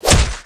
sound / weapons / genhit1.ogg
genhit1.ogg